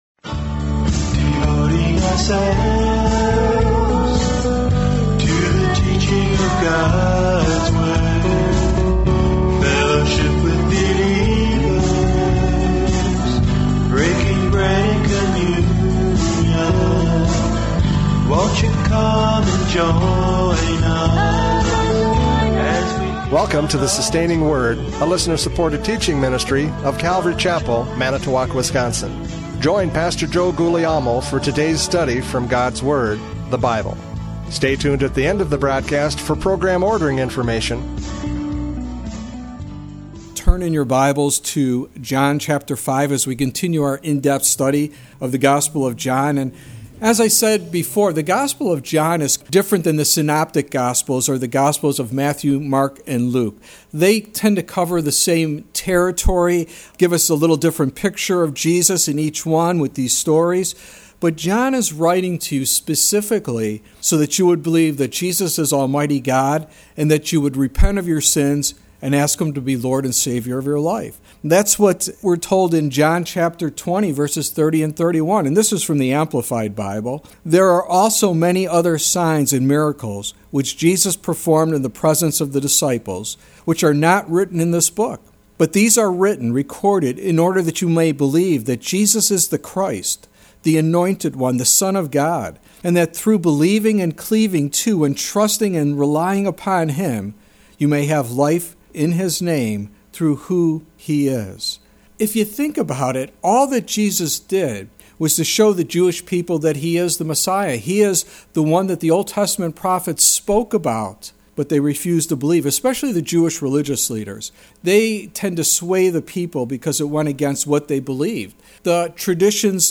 John 5:1-9 Service Type: Radio Programs « John 4:43-54 Faith or Faithless?